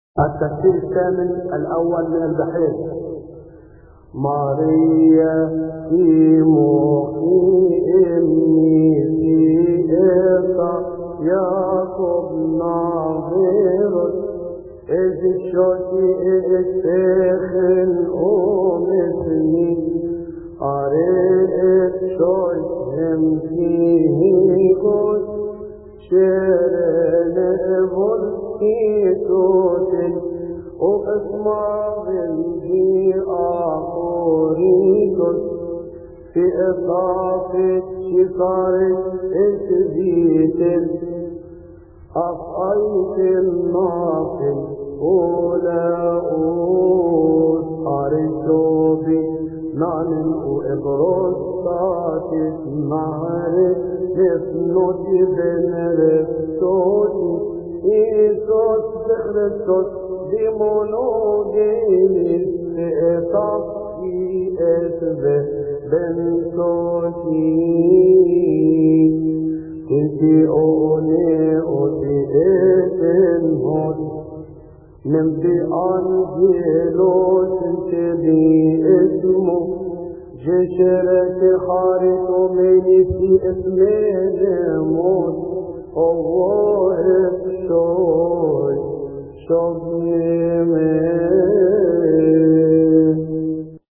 المرتل
يصلي في تسبحة عشية أحاد شهر كيهك